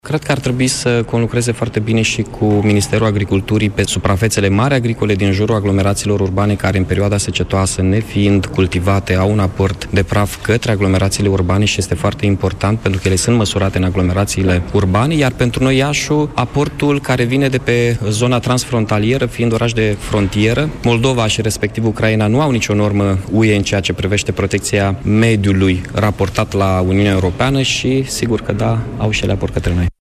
După consultările de la Ministerul Mediului, primarul municipiului Iaşi, Mihai Chirica, a afirmat că administraţiile locale nu pot lua singure toate măsurile necesare pentru o reducere a poluării aerului, problemă pentru care Bucureştiul, Iaşiul şi Braşovul riscă sancţiuni europene.